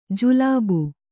♪ julābu